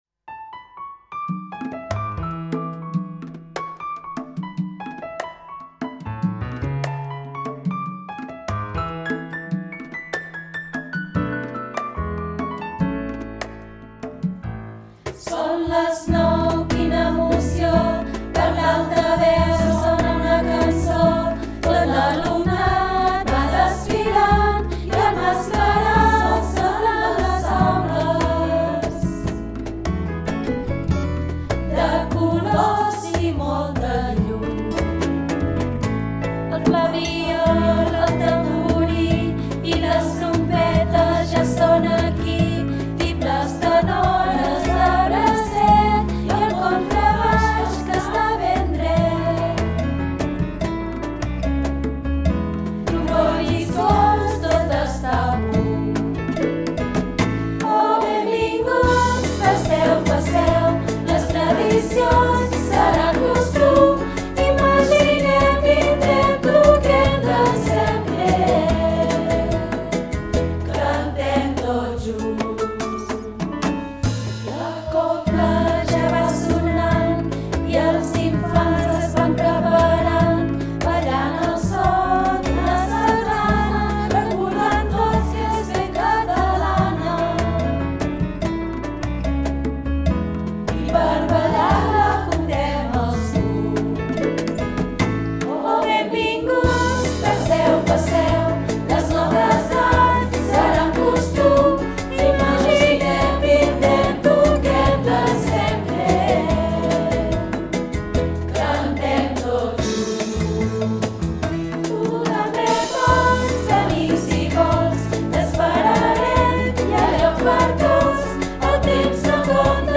base instrumental